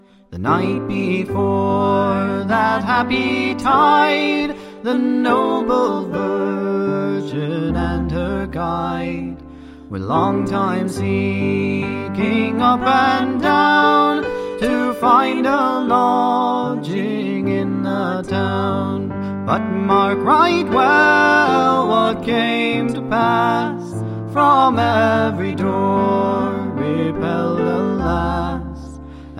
Flute, Vocals
Fiddle, Guitar, Vocals